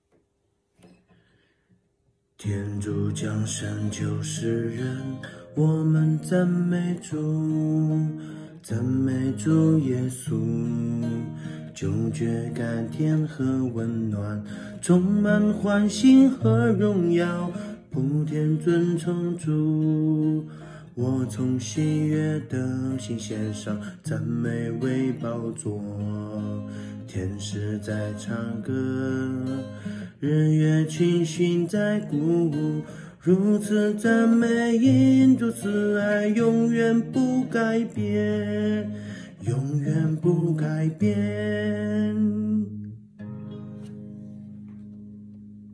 【原创圣歌】|《永远赞美主》
最近一段时间常失眠，所以情绪有所波动，在深夜时分，静心默想，亲近上主，发现心中神火炎炎，异常安慰，所以按照民谣的曲风，写下此歌。